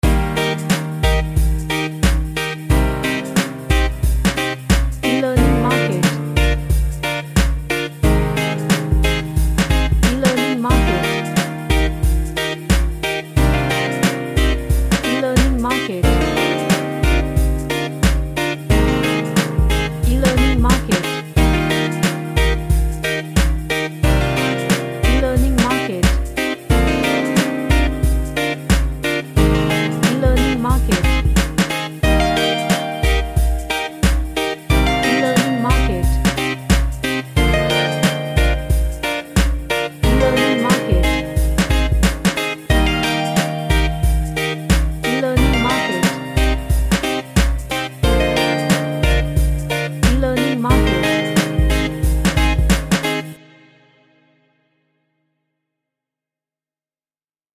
A happy reggae track with lots of groove.
Happy